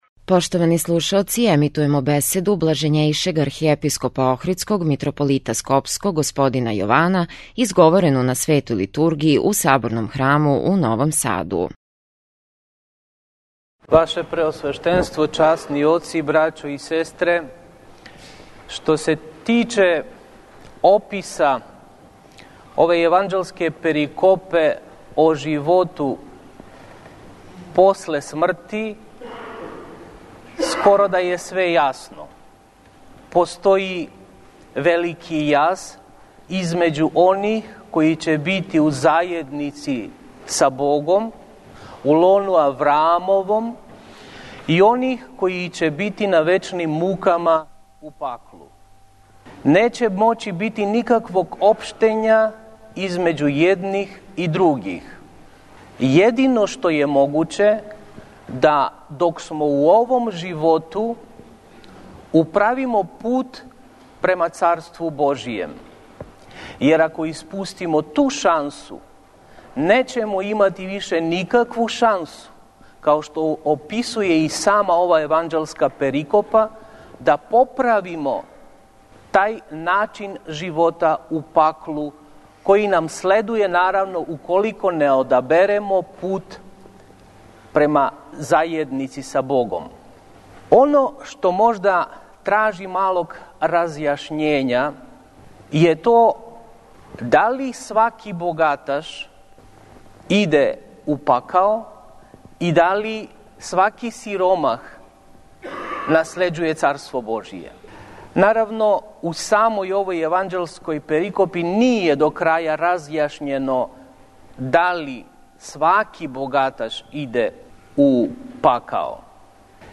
У недељу 22. по Педесетници, 13. новембра 2011. године, Његово Блаженство Архиепископ охридски и Митрополит скопски г. Јован началствовао је свештеним евхаристијским сабрањем у Саборном храму у Новом Саду, уз саслужење Његовог Преосвештенства Епископа бачког г. Иринеја, свештеникâ, ђаконâ и вернога народа Божјег.